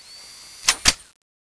change_to_a.wav